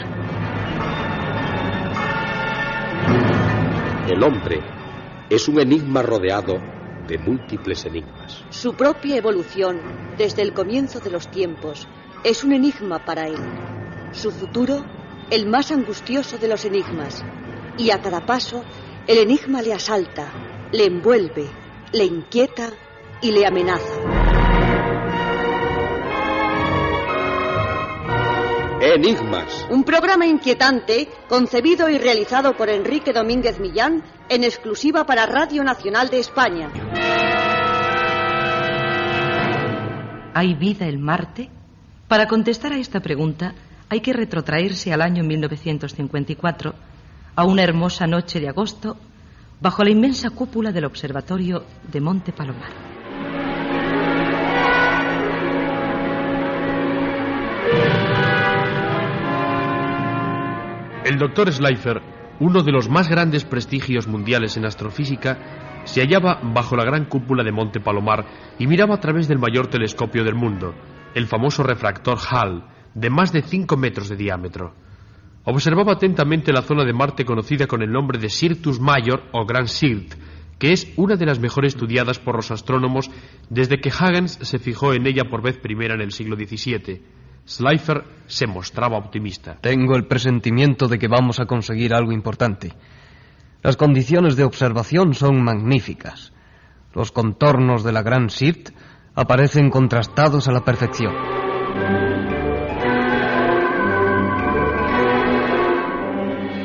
Careta del programa "Hi ha vida a Mart?".
Divulgació